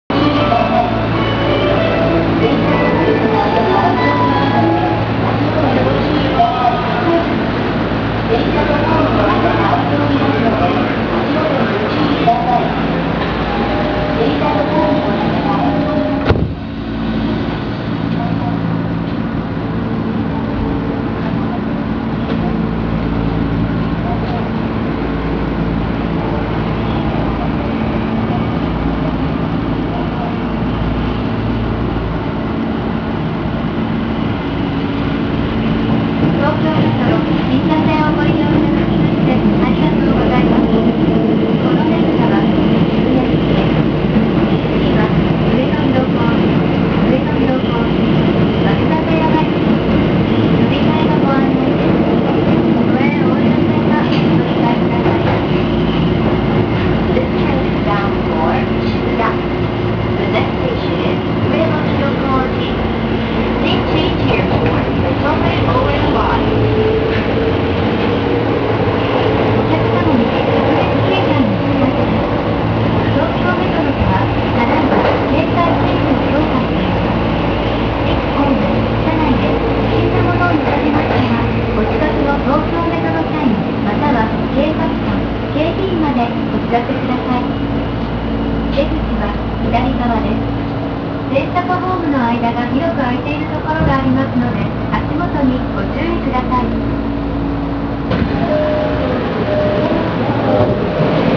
・1000系走行音
【銀座線】上野〜上野広小路（1分41秒：551KB）
先に登場した16000系や02系更新車と同様の東芝のPMSMです。…が、如何せんモーター音が静かすぎて最初の転調以外の音がよくわかりません。どちらの走行音も、勿論モーター車に乗っていますが、聞こえ方はこの程度です。